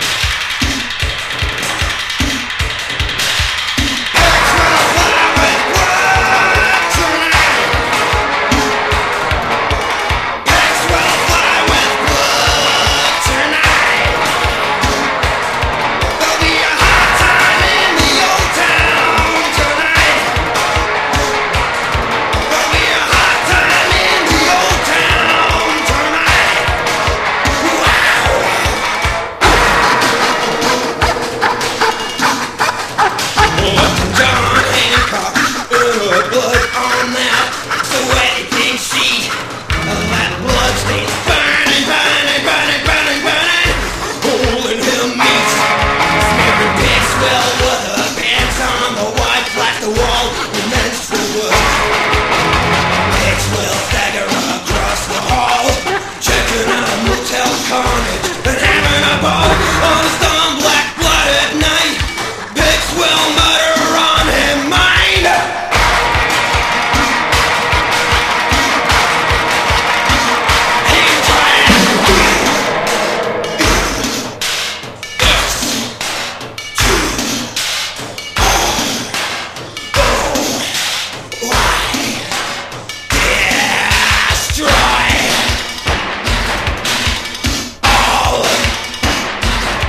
NEO SWING
ROCK'N' ROLL REVIVAL
ロッキー・シャープ好きも素通り厳禁のパーティ・オールディーズ・ナンバーてんこ盛り！